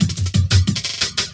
17 LOOP08 -L.wav